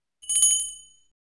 Small Bell Sound Effect Free Download
Small Bell